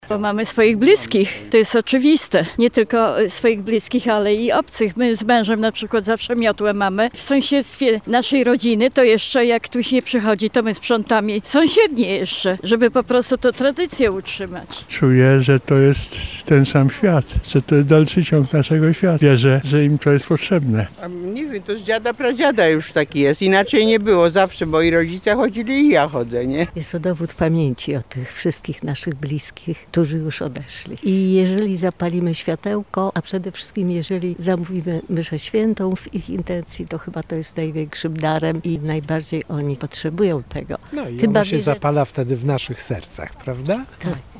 Zapytaliśmy mieszkańców Lublina, co ich zdaniem sprawia, że co roku tłumnie odwiedzamy cmentarze:
SONDA